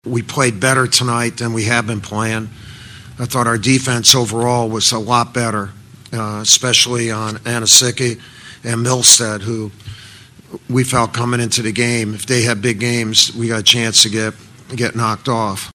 Coach K spoke to the media after the game and discussed what went into the team’s nail biting win.